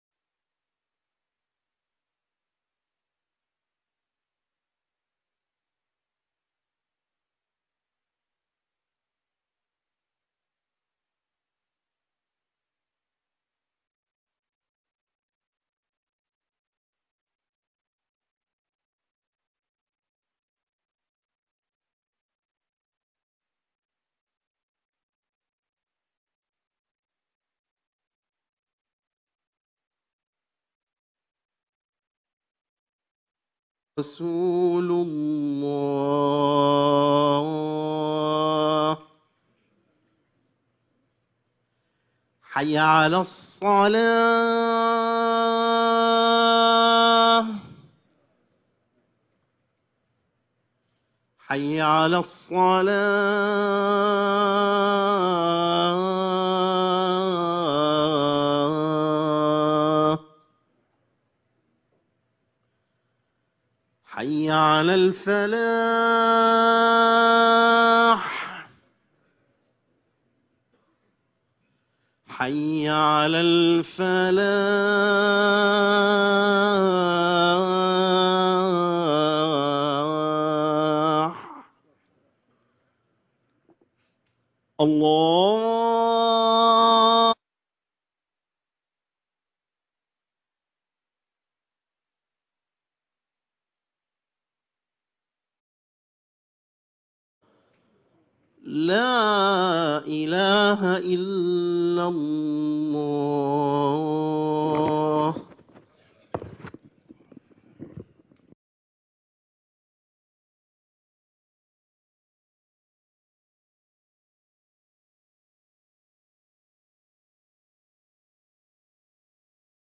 خطبة الجمعة - من ابي طالب